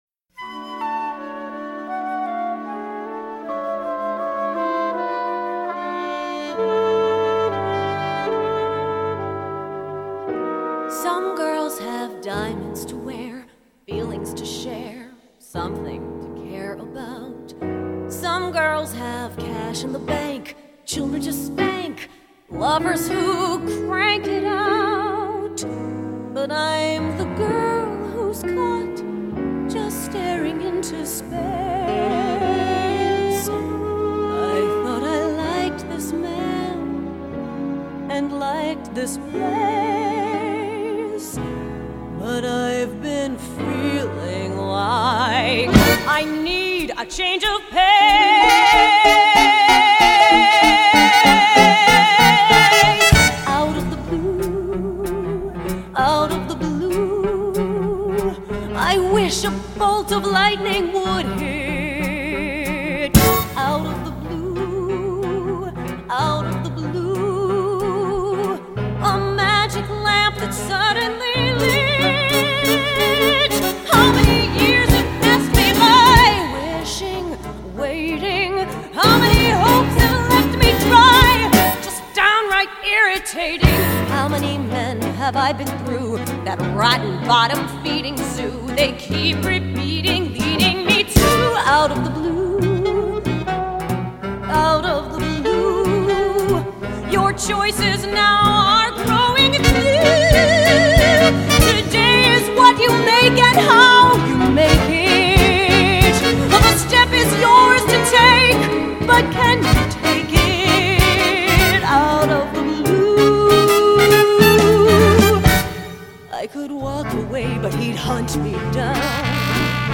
1999   Genre: Musical   Artist